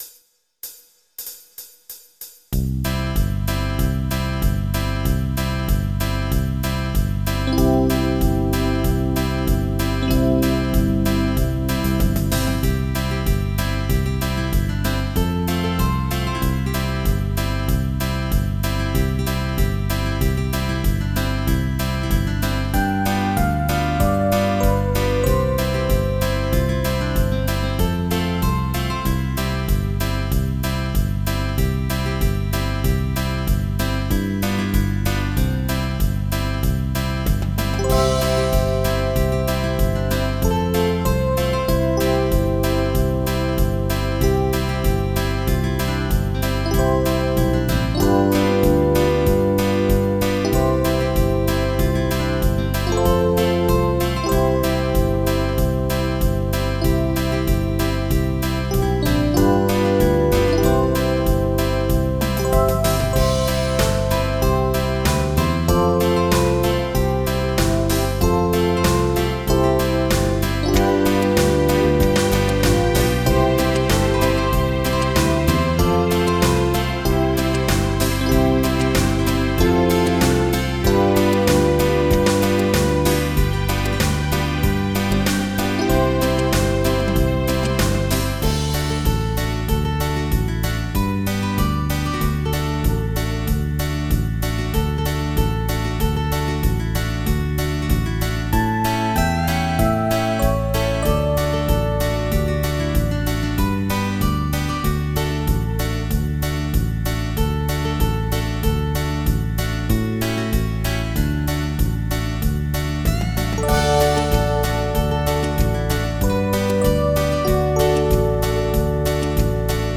Oldies
MIDI Music File